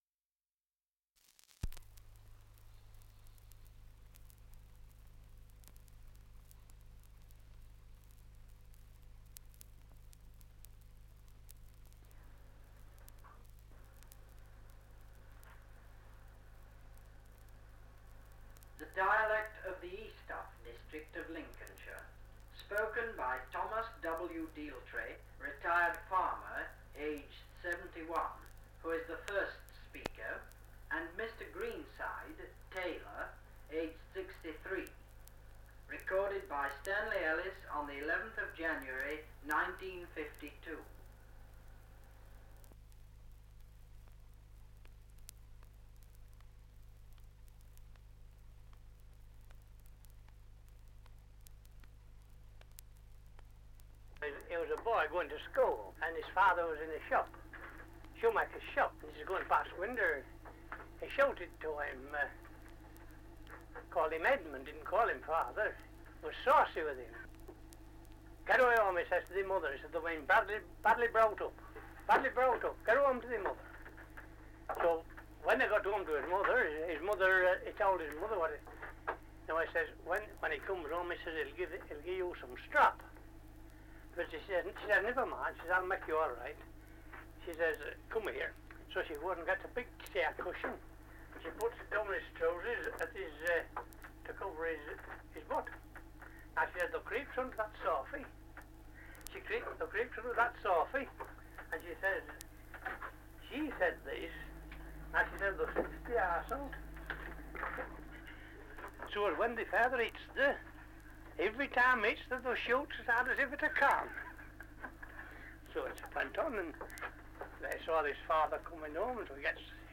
Introduction to Lincolnshire Dialect. Survey of English Dialects recording in Eastoft, Lincolnshire
78 r.p.m., cellulose nitrate on aluminium